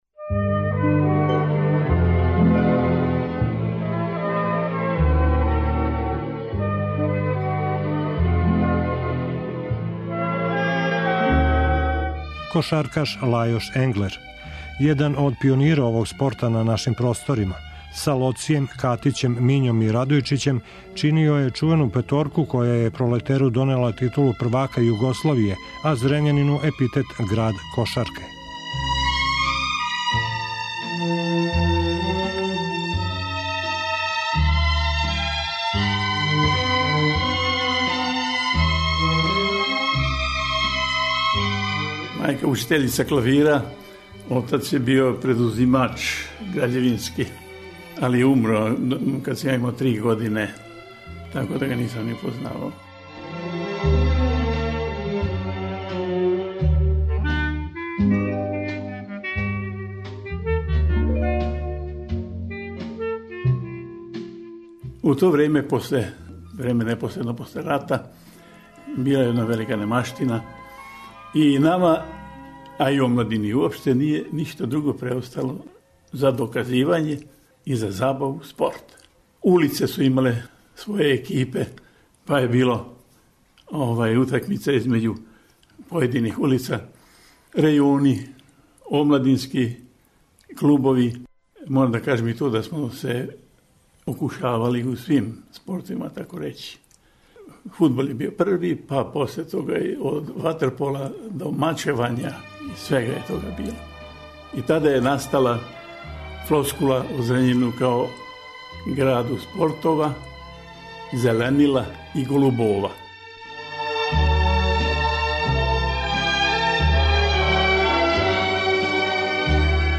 Радио Београд 1 од 16 до 17 часова.